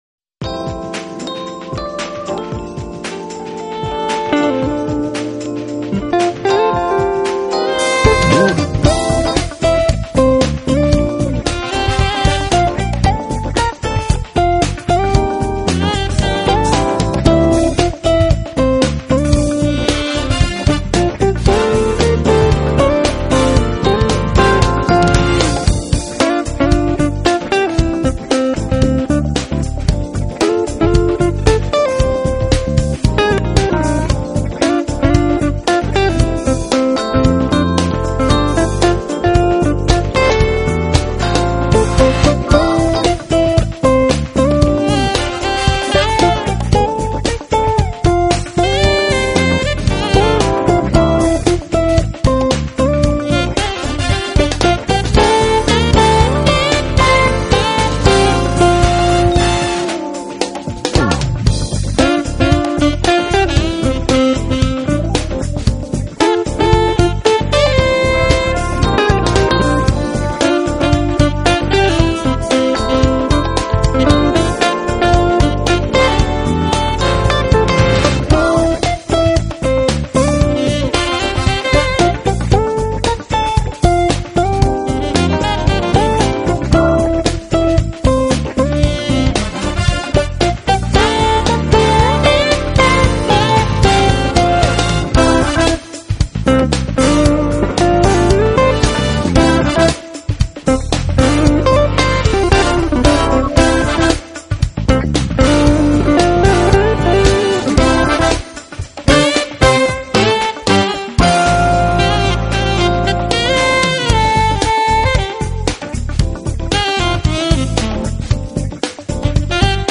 Genre: Jazz, Smooth Jazz, Jazz Instrument, Guitar